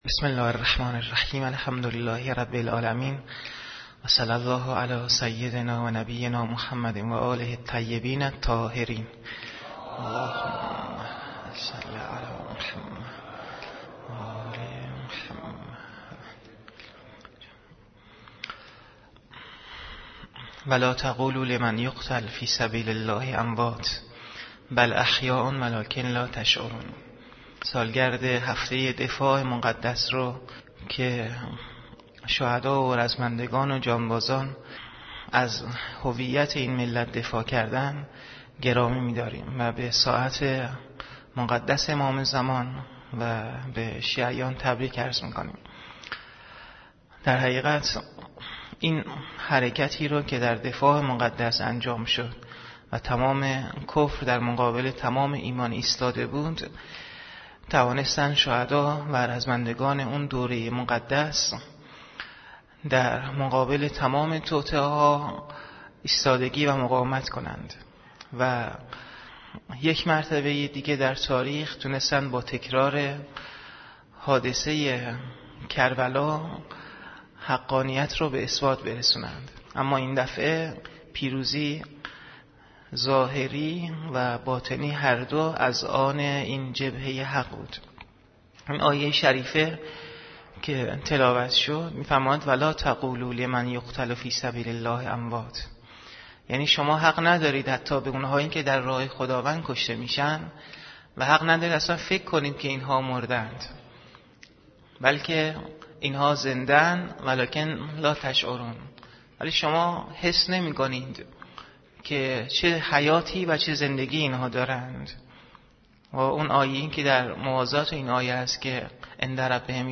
مجموعه صوتي سخنراني